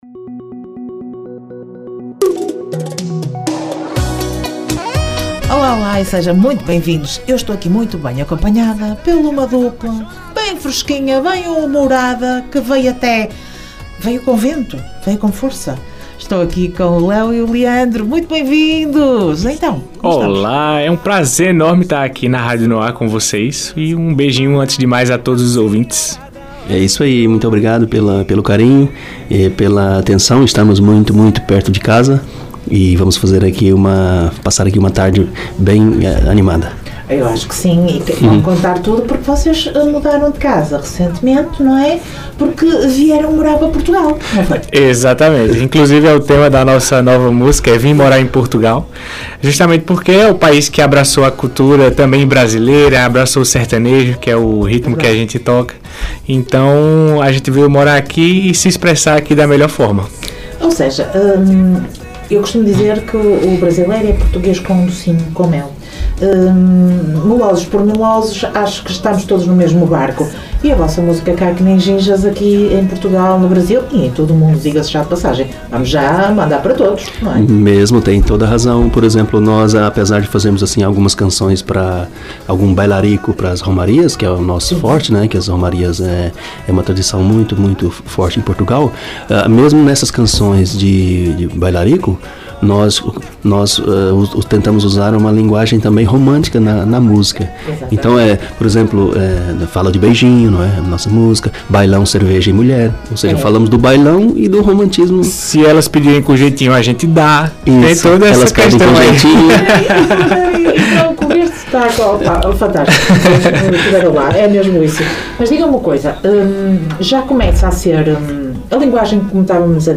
Entrevista Léo & Leandro dia 12 de Novembro.